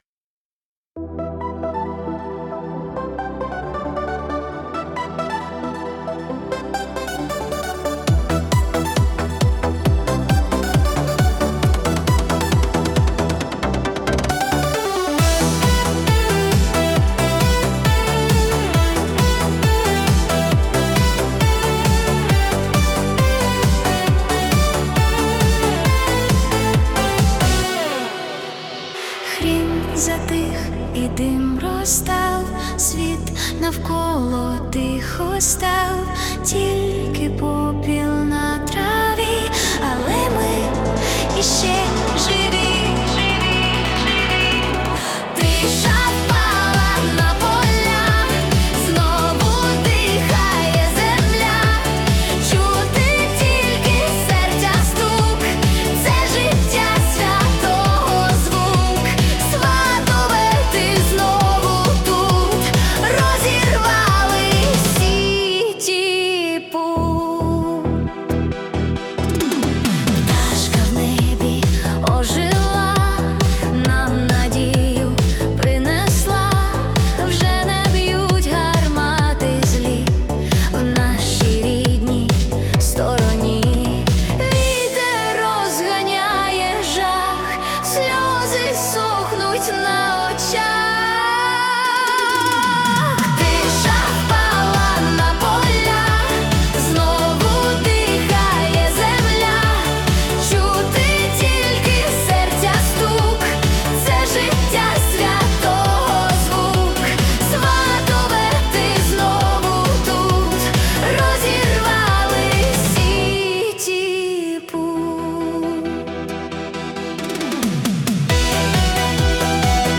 Synth-pop / Rock / Folk Elements